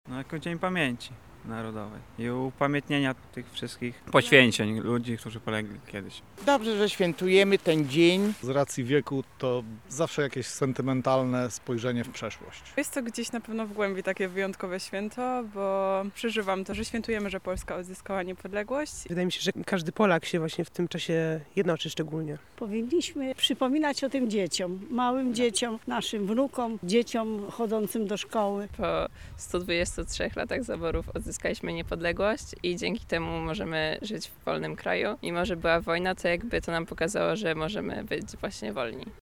11 listopada – co oznacza dla Polaków? [SONDA]
Zapytaliśmy bywalców Ogrodu Saskiego, jak ją postrzegają:
sonda